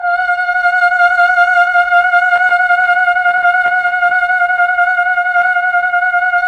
VOX_Chb Fm F_6-L.wav